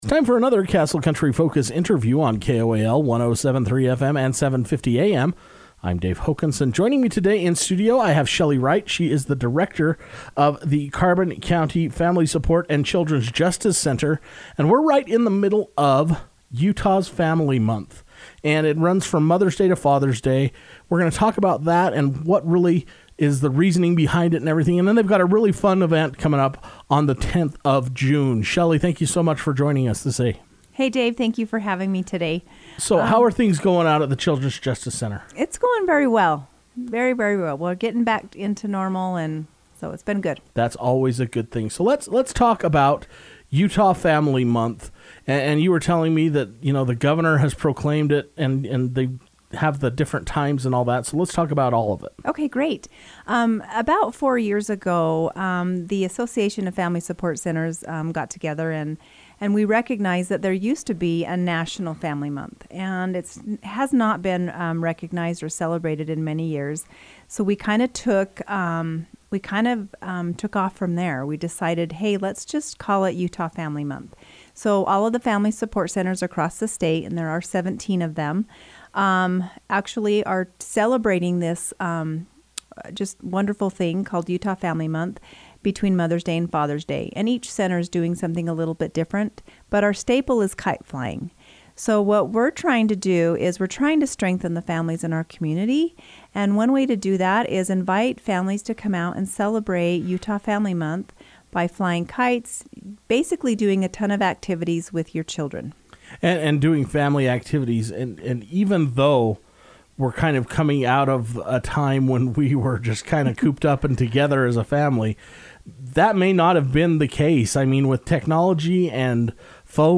took time to come into the Castle Country Radio Station to share details about their upcoming event.